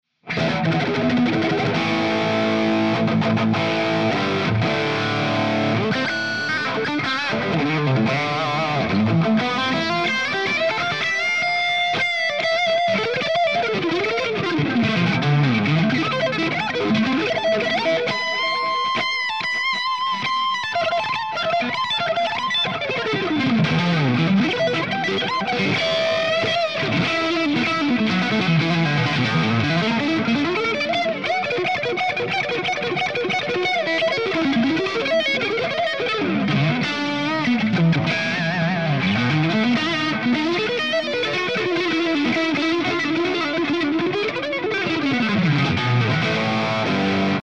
The M1 Epona pack includes captures ranging from clean to full metal hi gain and everything in between plus my personal YouTube IR that I use in my demos are also included.
Improv
RAW AUDIO CLIPS ONLY, NO POST-PROCESSING EFFECTS